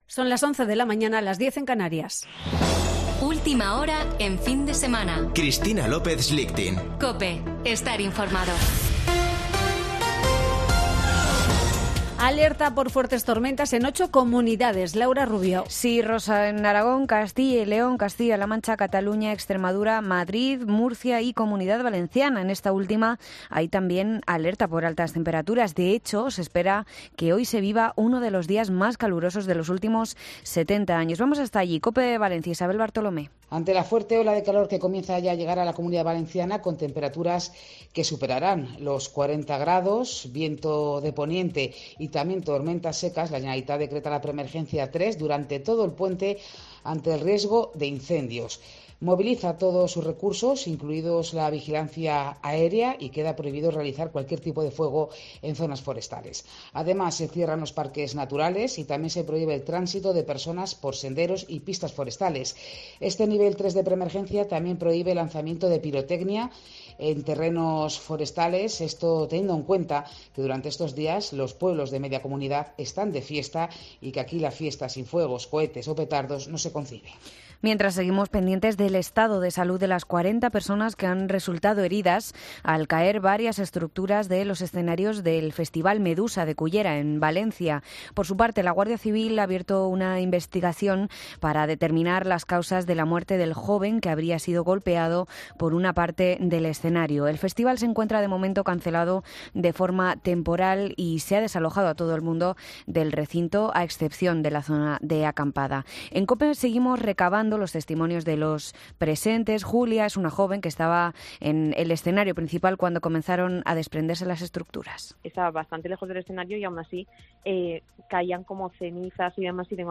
Boletín de noticias de COPE del 13 de agosto de 2022 a las 11.00 horas